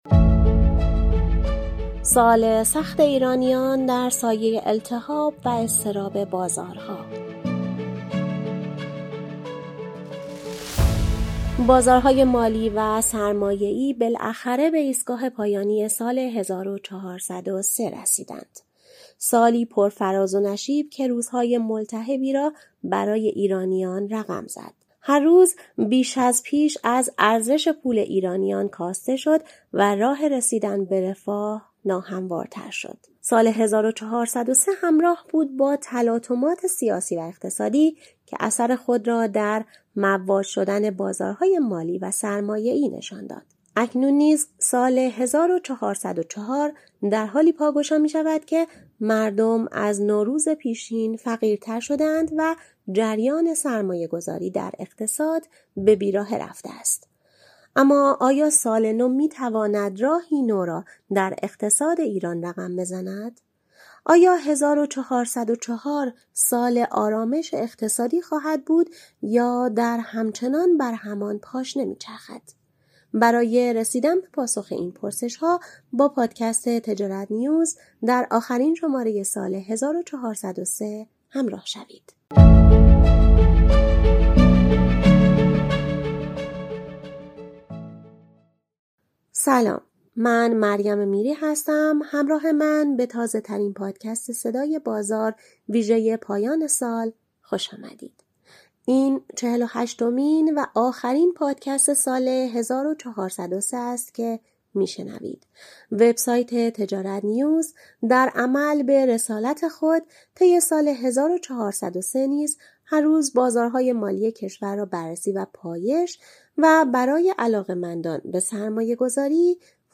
این بار نیز در واپسین شماره از پادکست صدای بازار با تحلیل وضعیت بازارهای ارز، طلا، سکه، سهام، مسکن و خودرو در سال 1403 همراه شوید و چشم‌انداز سال 1404 را از زبان کارشناسان بشنوید.